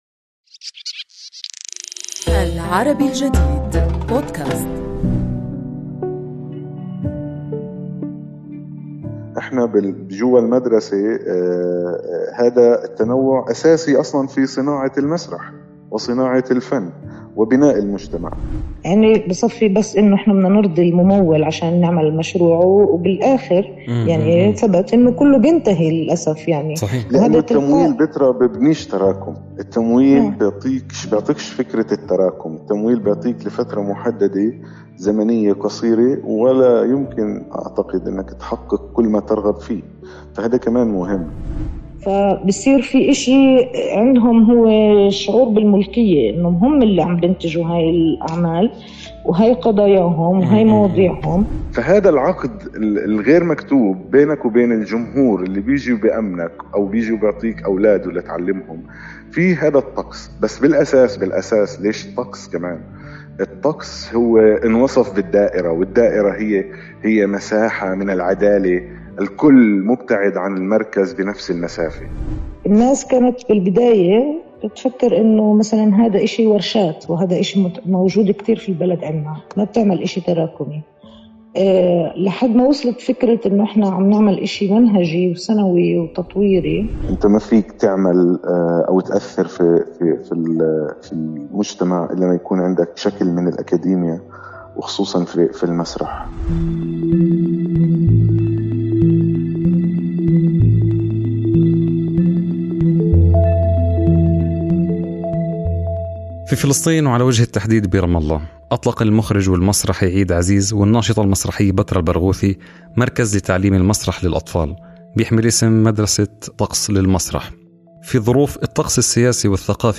حوارنا اليوم في بودكاست "فيه ما فيه"